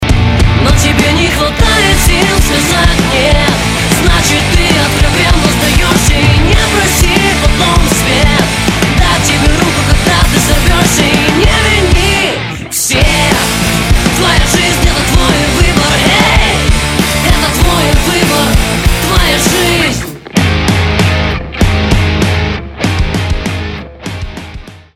• Качество: 192, Stereo
громкие
Драйвовые
мотивирующие